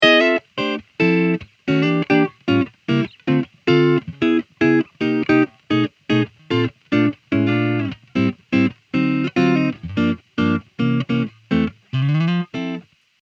Jazz chord melody